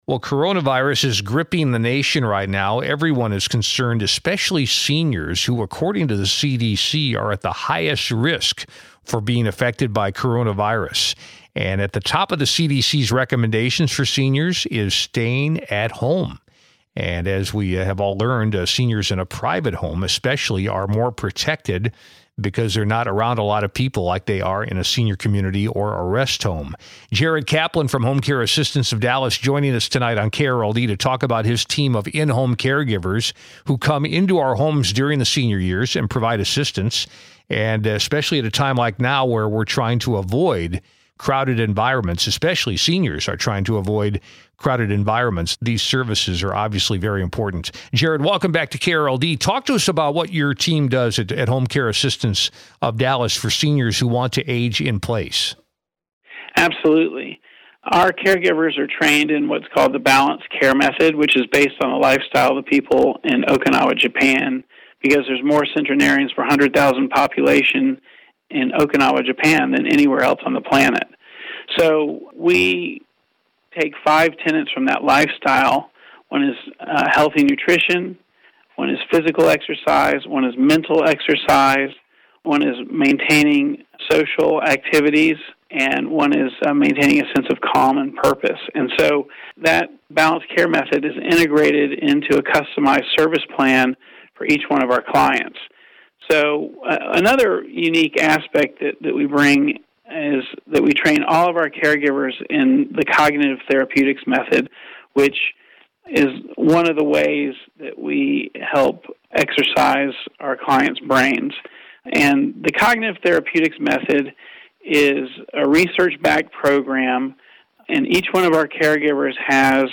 Here is a radio interview with a client and his wife sharing the powerful positive impacts that Home Care Assistance care managers and caregivers deliver.
Consumer Team is broadcast Saturdays from 6 to 8 PM on 1080 KRLD Radio in Dallas.